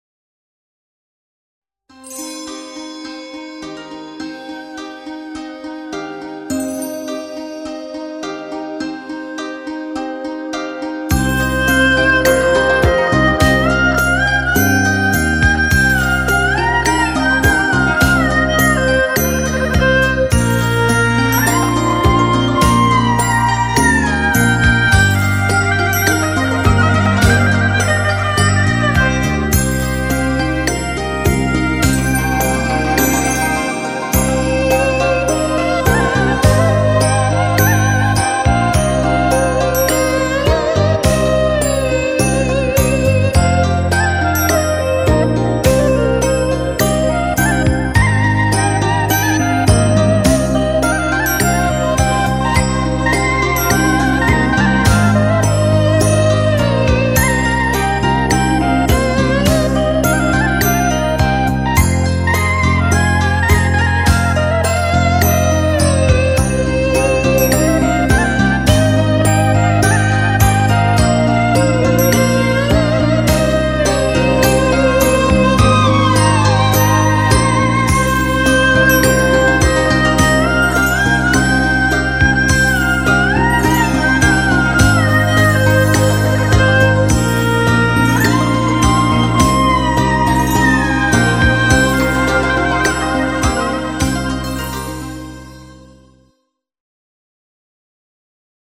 chine - nostalgique - calme - melancolie - romantique